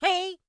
Npc Catattack Sound Effect
npc-catattack-2.mp3